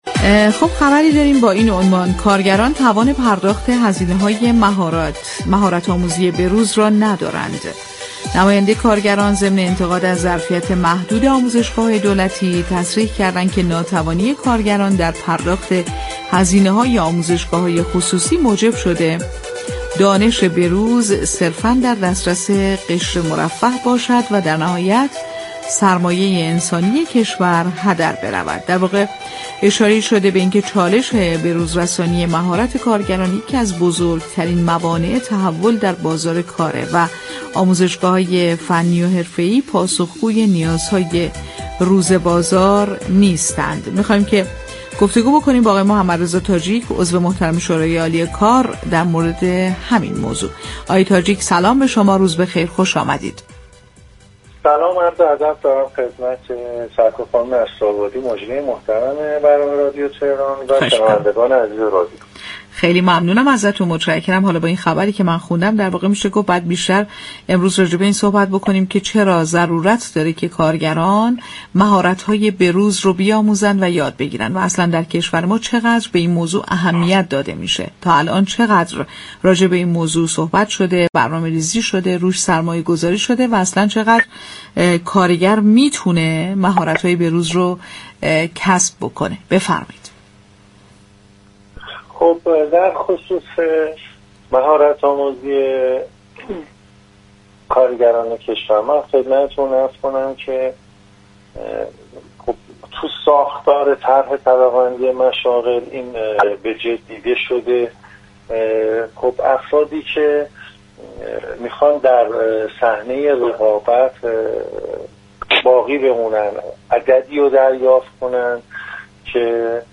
در گفتگو با رادیو تهران